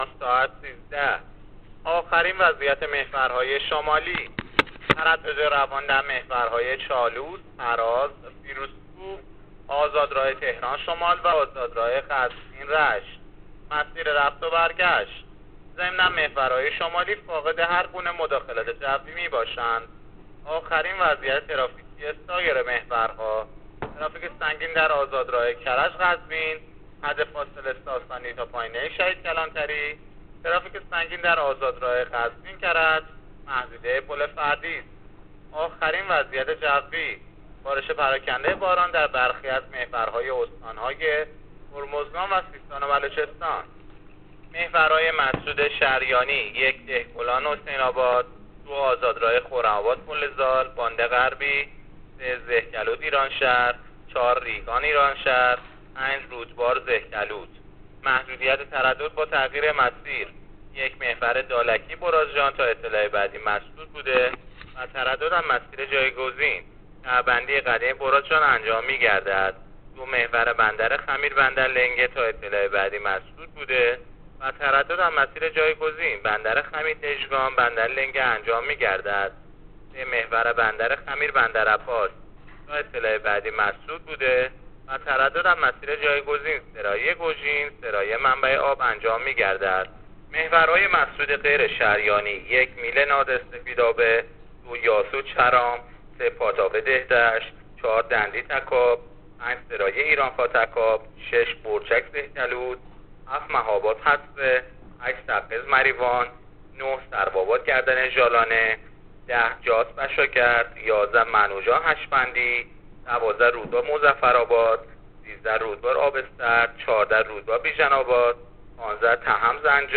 گزارش رادیو اینترنتی از آخرین وضعیت ترافیکی جاده‌ها تا ساعت ۱۳ بیست‌ونهم دی؛